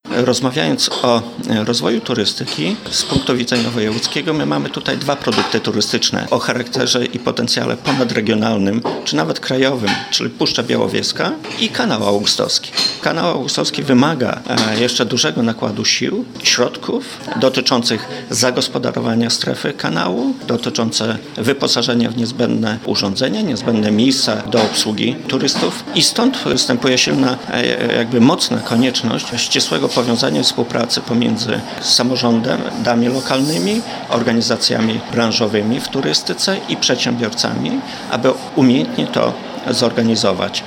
– Kanał Augustowski to produkt turystyczny o potencjale krajowym, ale wymaga on jeszcze dużego nakładu środków – mówi Bogdan Dyjuk, radny sejmiku wojewódzkiego.
Wiadomości